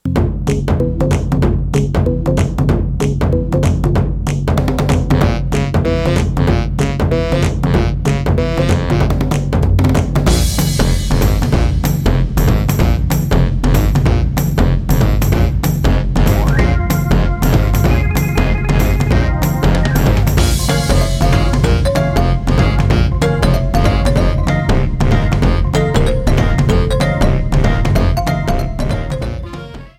Ripped from the ISO
Faded in the end
Fair use music sample